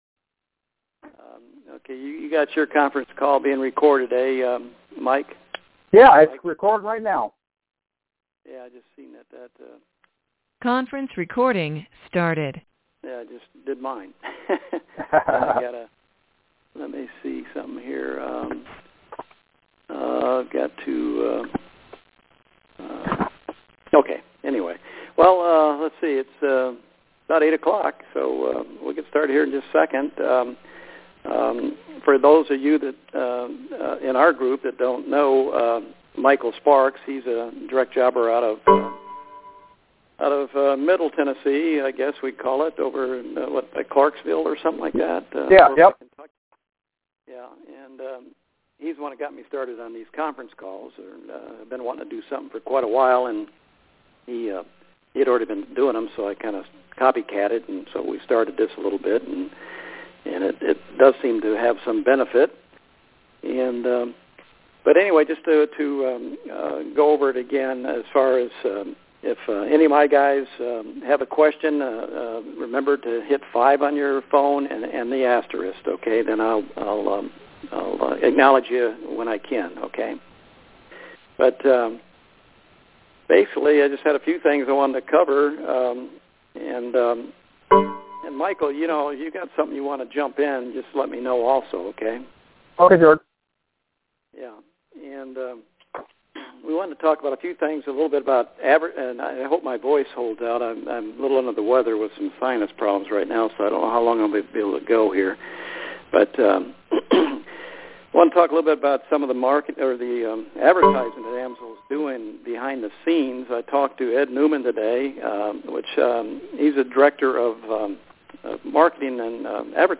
Sparks Team AMSOIL Dealer Training Call | September 15th, 2014